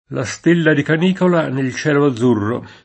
canicola [kan&kola] s. f. — nell’uso più ant., anche canicula [kan&kula] (che riproduce esattam. il lat. canicula «cagnolina») — con C- maiusc. come nome di stella (Sirio, la più brillante della costellaz. del Cane): la stella di Canicola Nel cielo azzurro [